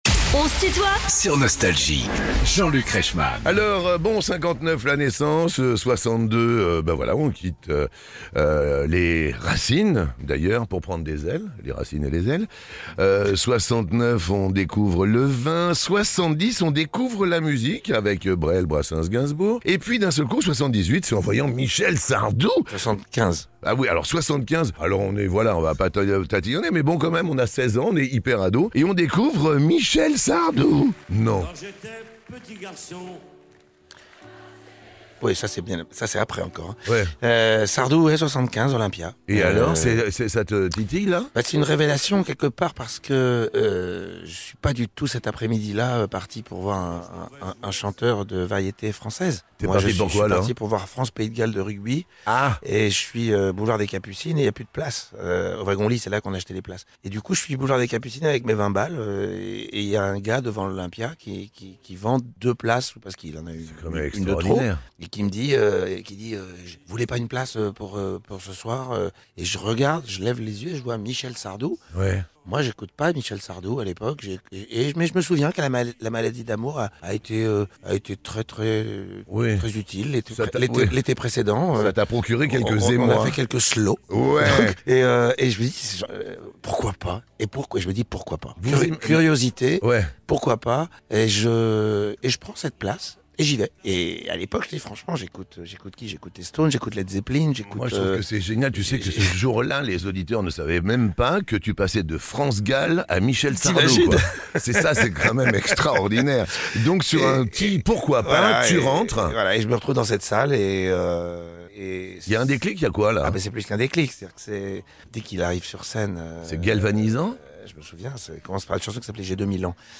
Invité de "On se tutoie ?..." avec Jean-Luc Reichmann, Patrick Bruel dévoile les moments forts de la carrière